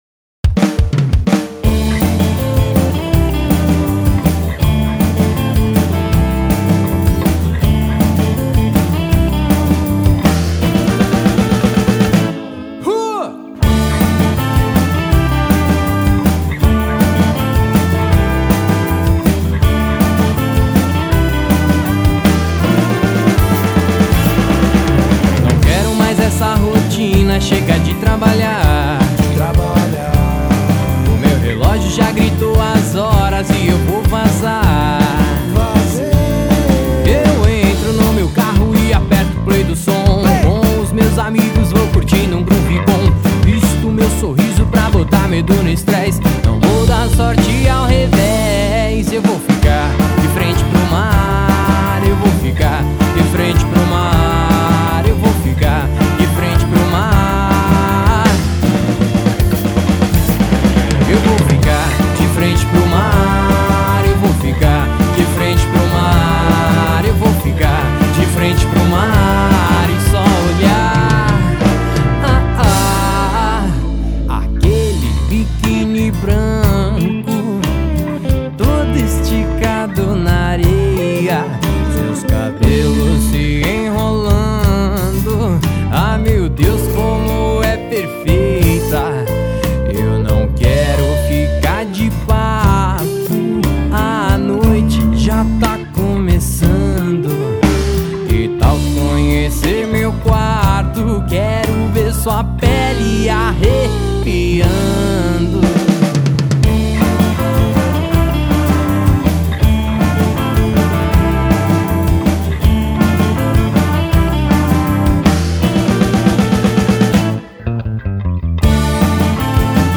EstiloSamba Rock